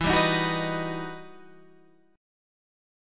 E Minor Major 9th